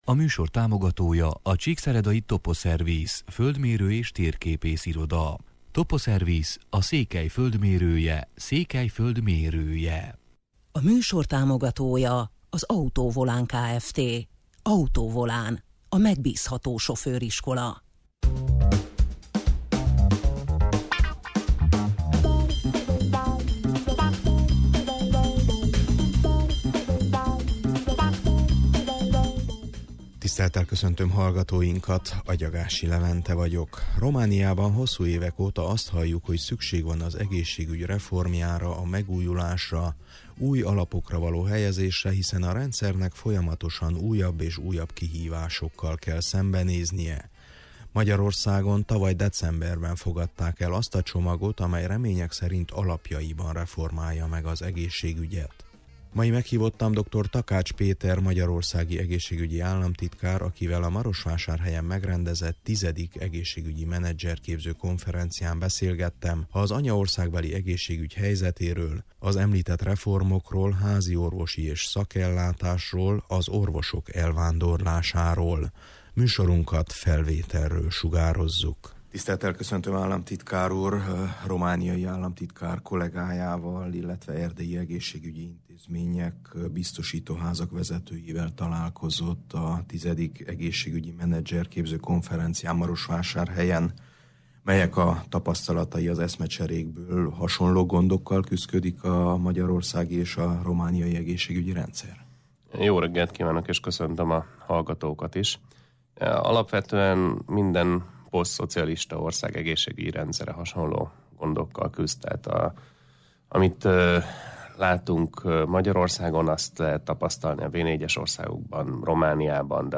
Mai meghívottam Dr. Takács Péter magyarországi egészségügyi államtitkár, akivel a Marosvásárhelyen megrendezett X. Egészségügyi Menedzserképző Konferencián beszélgettem az anyaországbeli egészségügy helyzetéről, az említett reformokról, háziorvosi- és szakellátásról, az orvosok elvándorlásáról.